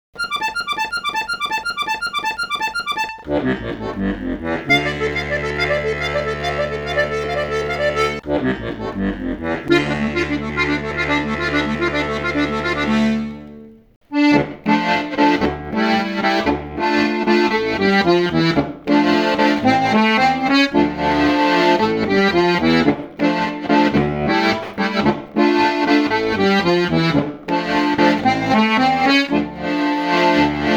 • 11 leicht bis mittelschwere Volksmusikstücke